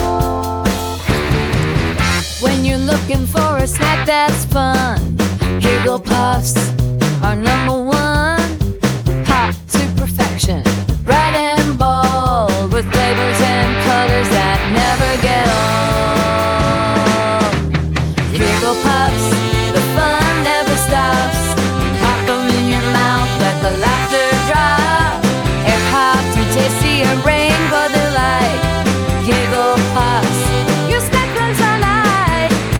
Finally, we created a full jingle and used Text to Audio platform UDIO to convert the text into a fully performed track.
Here is the full Giggle Puffs jingle we created in real-time.